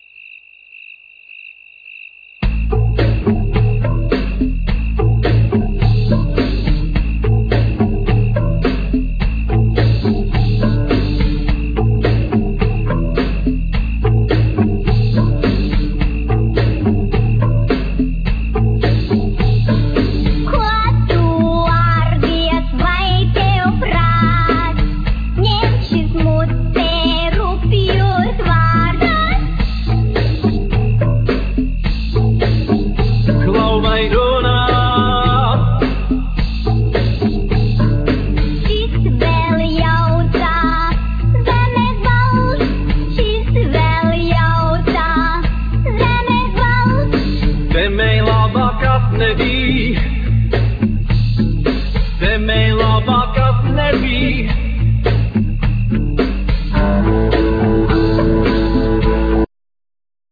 VocalViolin
Vocal,Kokle(box zither),Bagpipes,acordion,Keyboards